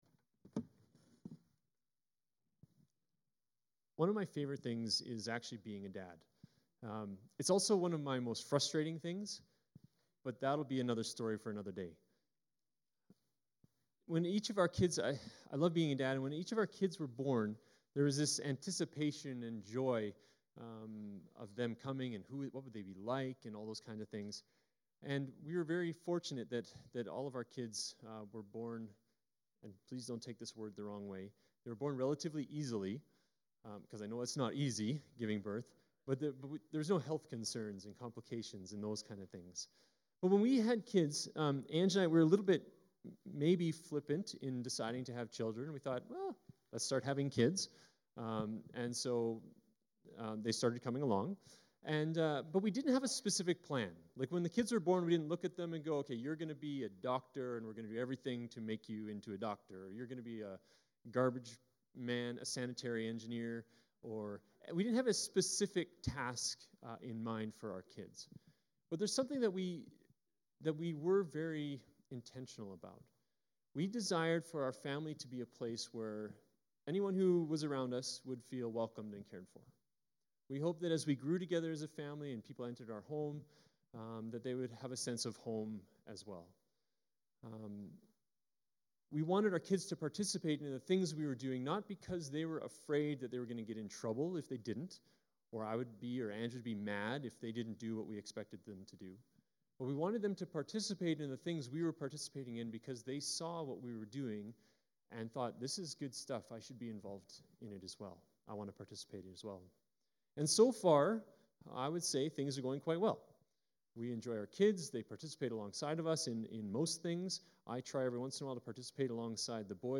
Archived Sermons | Crescent Heights Baptist Church